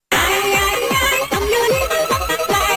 /public-share/packwiz-modpacks/tbm-minecraft-mods/resourcepacks/TBMPack/assets/minecraft/sounds/ambient/cave/
cave3.ogg